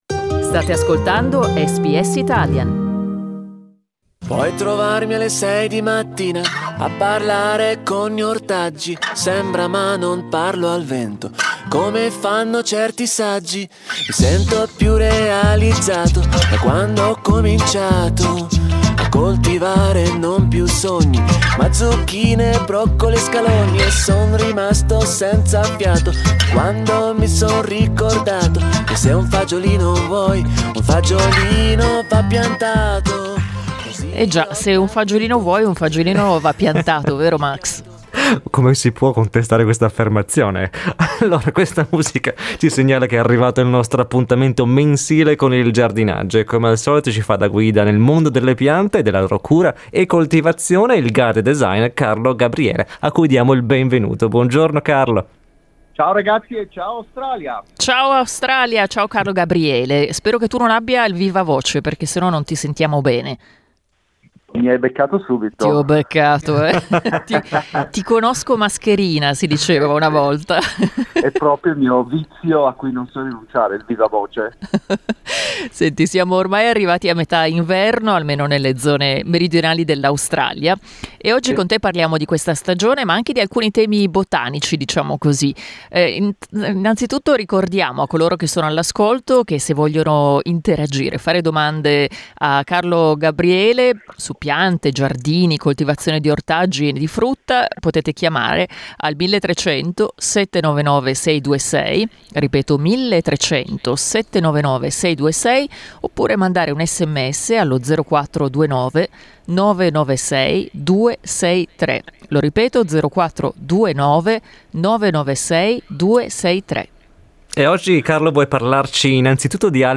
in diretta su SBS Italian